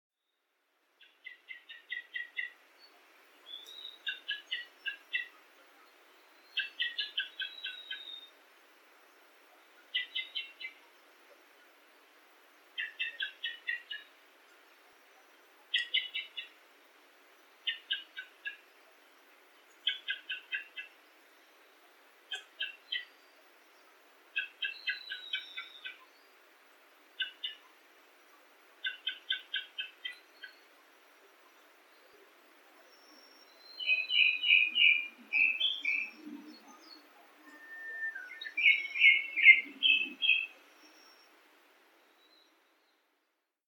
◎　クロツグミ【黒鶫】　Japanese Thrush　スズメ目ヒタキ科ツグミ属　全長:22ｃｍ
【録音②】　2020年6月21日　埼玉県　有間ダム　地鳴きからさえずりへ
「キョ キョ キョ」「チー」などと鳴く